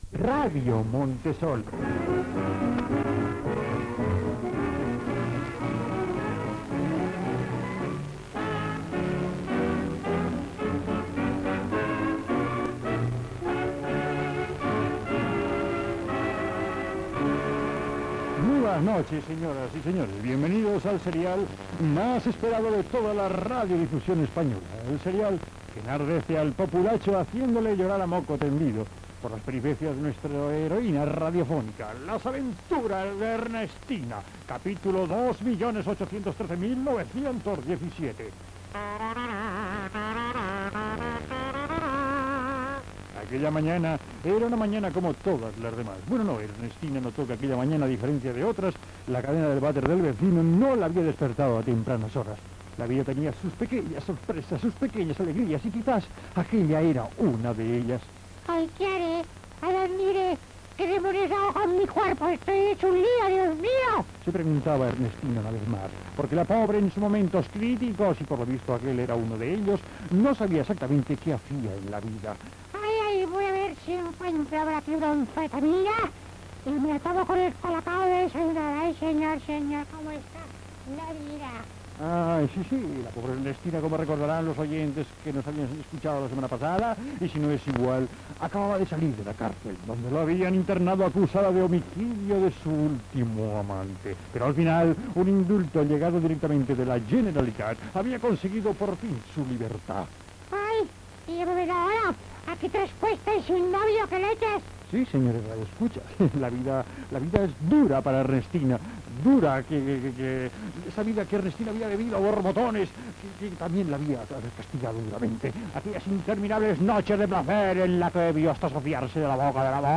serial humorístic
Connexió amb el Tio Lucas que està a l'estació de Pubilla Cases del Metro
Gènere radiofònic Entreteniment